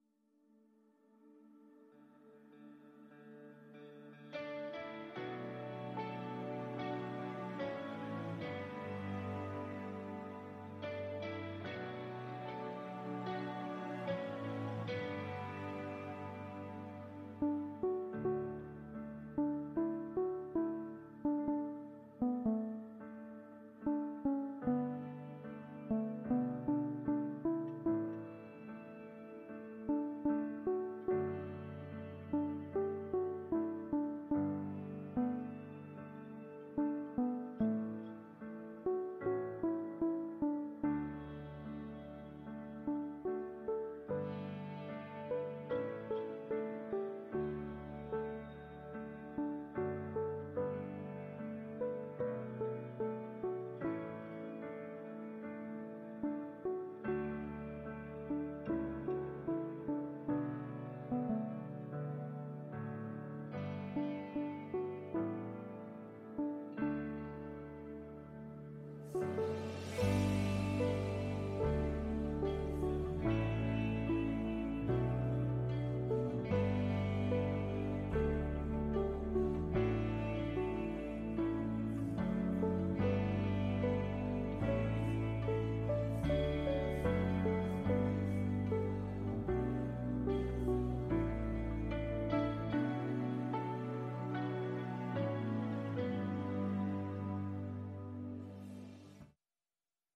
These are practice tracks that you can use to learn the melody or harmony parts to various songs.
Love of God Soloist Practice Track - Soloist Practice Track
loveofgodsolopractice.m4a